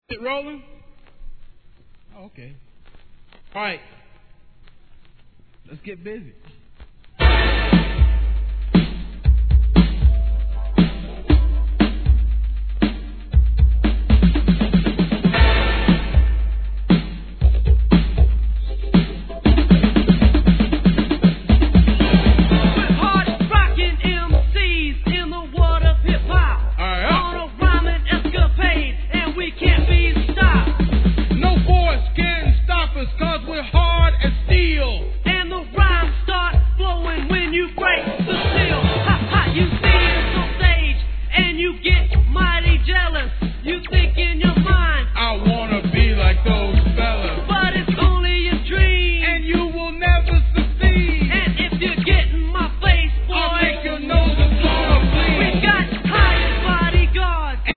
HIP HOP/R&B
テキサス産OLD SCHOOLなマイナーHIP HOP!!